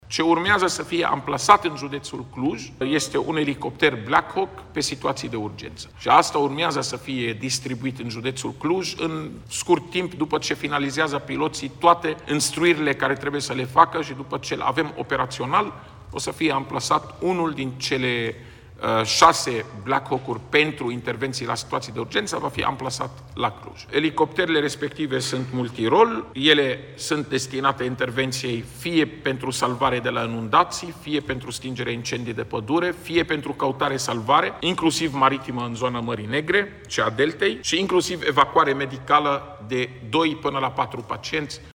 Șeful Departamentului pentru Situații de Urgență, Raed Arafat: Elicopterul va deveni operațional după ce piloții vor finaliza toate instruirile